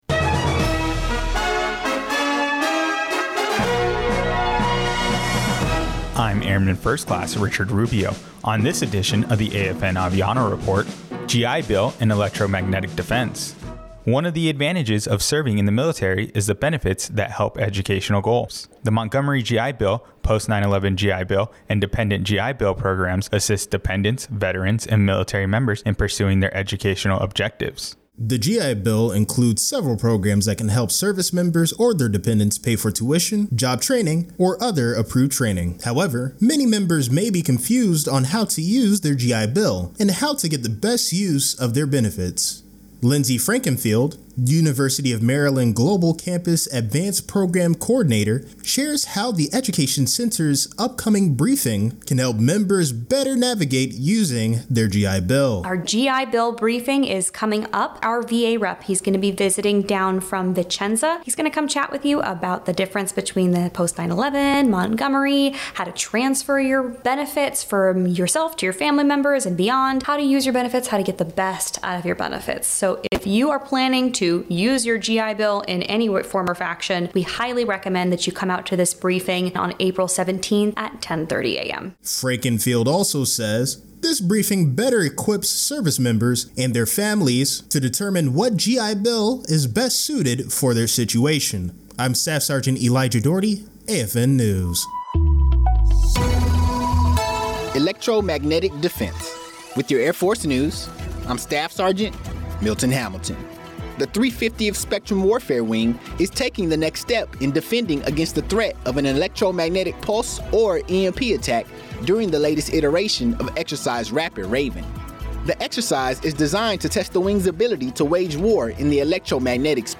American Forces Network (AFN) Aviano radio news reports on the Aviano Education and Training Center's GI Bill briefing.